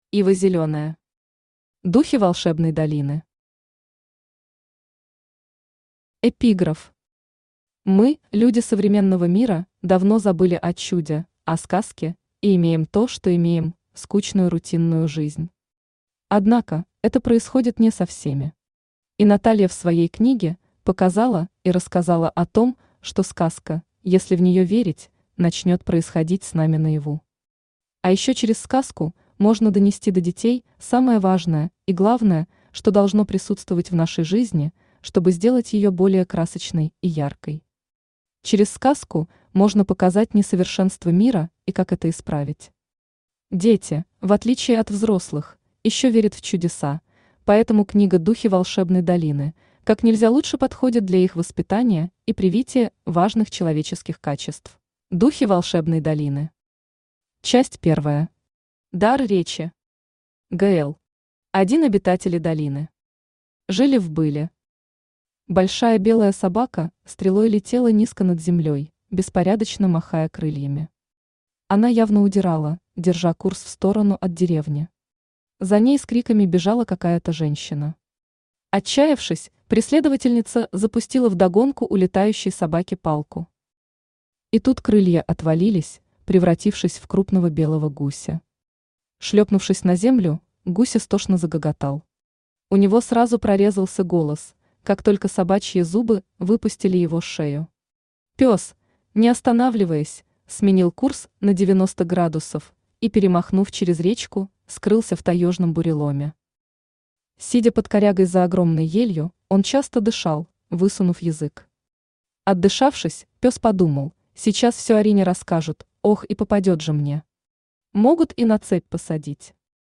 Аудиокнига Духи волшебной долины | Библиотека аудиокниг
Aудиокнига Духи волшебной долины Автор Ива Зелёная Читает аудиокнигу Авточтец ЛитРес.